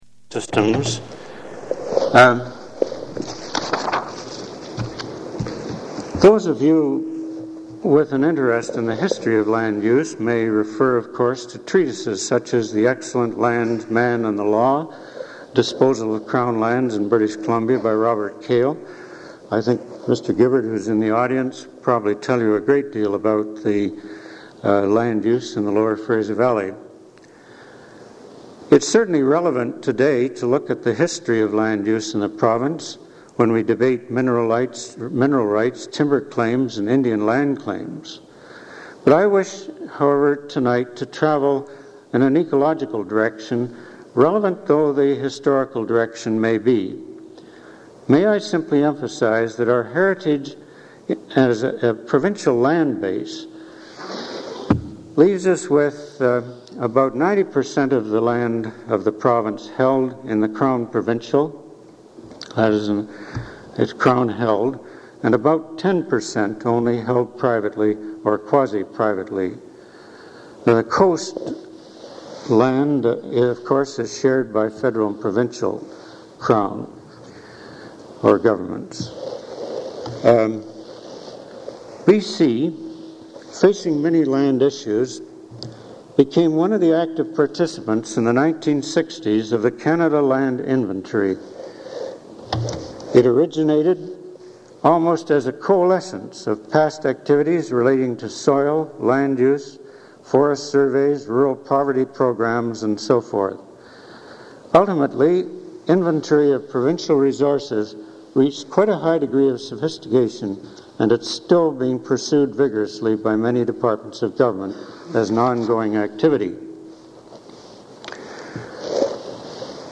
Vancouver Institute lecture